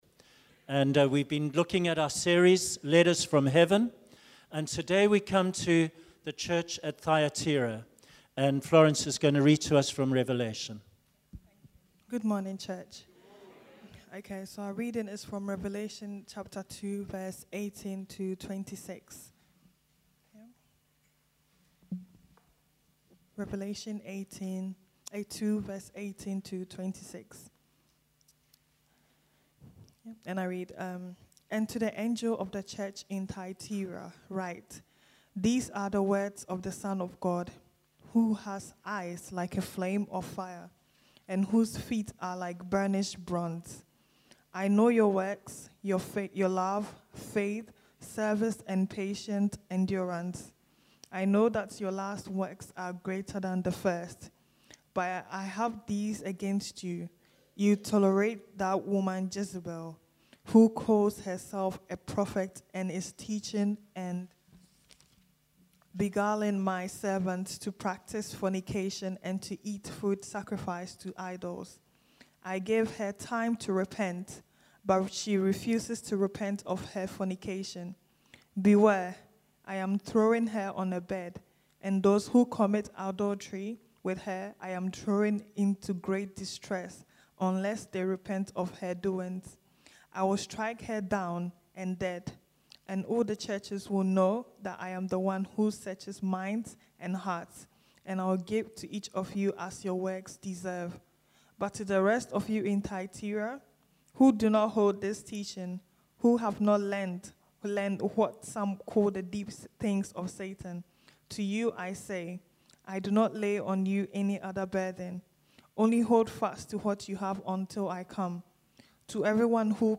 A series of sermons on the letters from the Book of Revelation.